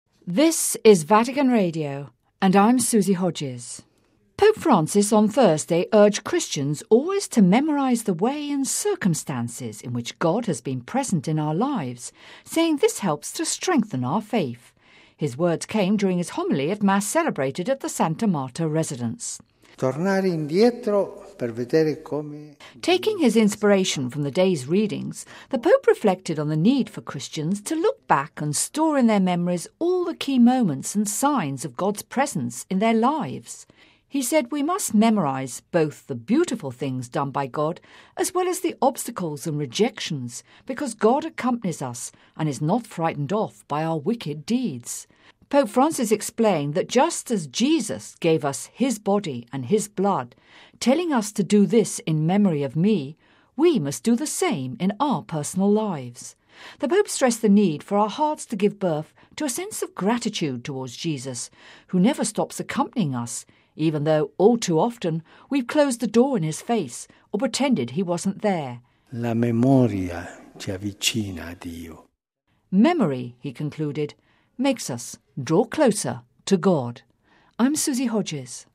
(Vatican Radio) Pope Francis on Thursday urged Christians always to memorize the way and circumstances in which God has been present in our lives, saying this helps to strengthen our faith. His words came during his homily at Mass celebrated at the Santa Marta residence.